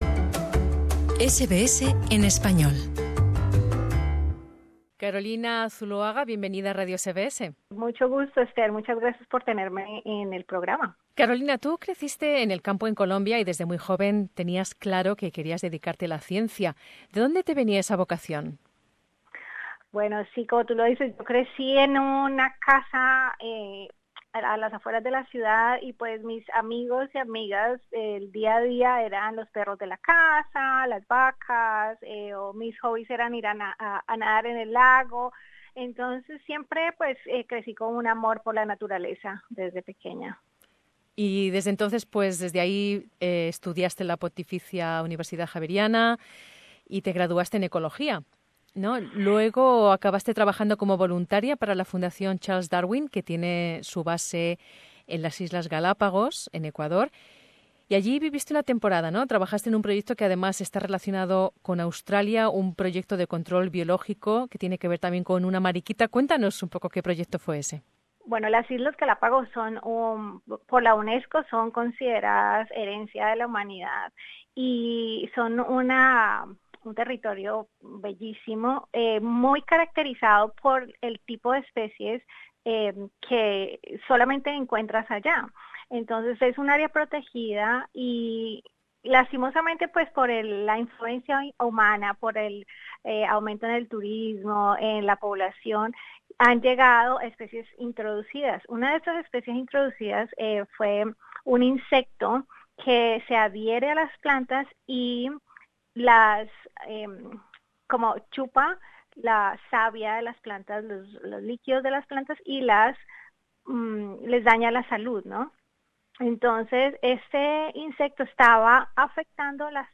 La organización Cultural Infusion habilita a científicos migrantes cualificados pero poco reconocidos, para que apliquen sus conocimientos a programas educativos en las escuelas. Escucha aquí el podcast con la entrevista.